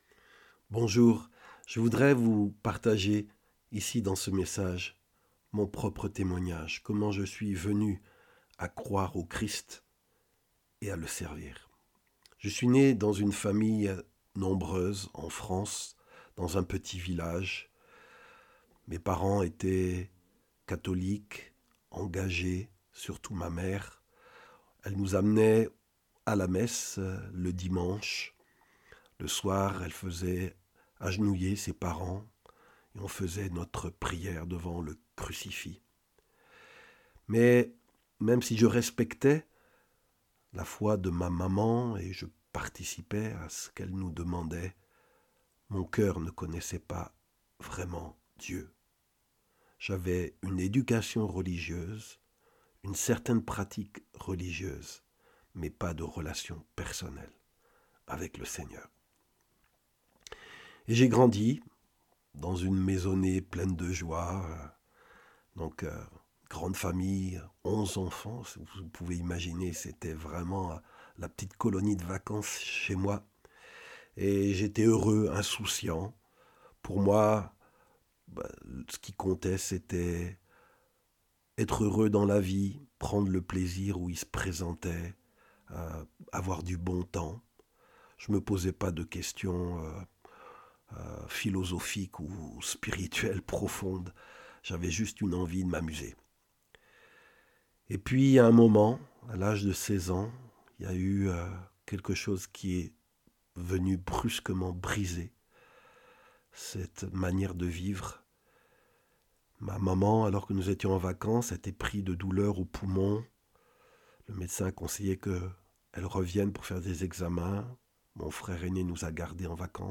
Récit témoignage de ma rencontre et conversion au Christ.